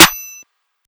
Snare ElbeDock.wav